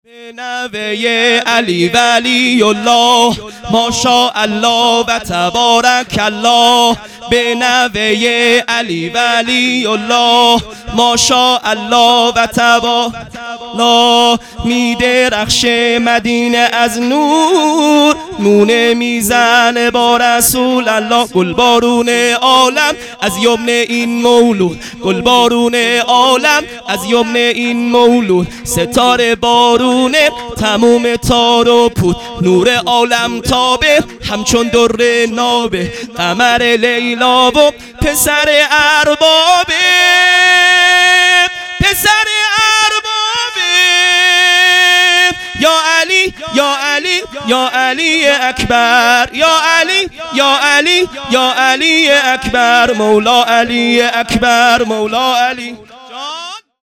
هیئت مکتب الزهرا(س)دارالعباده یزد - سرود ۳ | به نوه ی علی ولی الله مداح